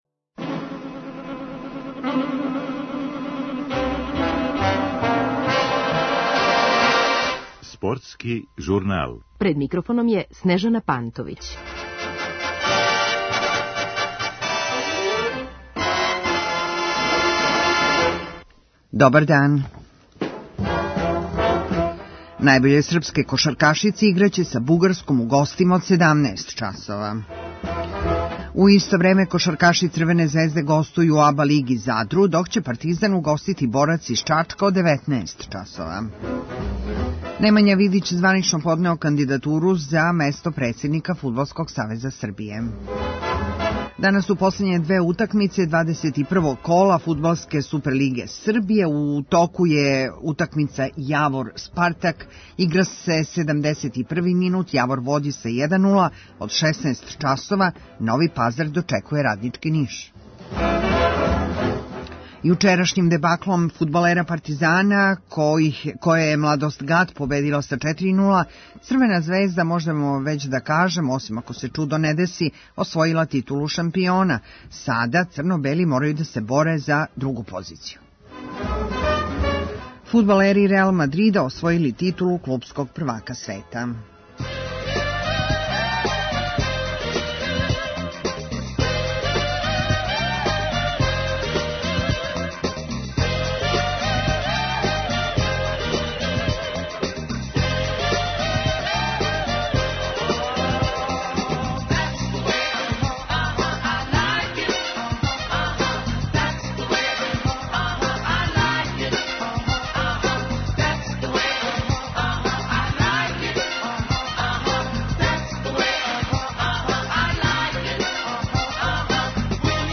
У Спортском журналу говоримо о кошаркашким и фудбалским акутелностима, али о ватерполу, ритмичкој гимнастици – лепу причу чућемо од легендарне Милене Рељин.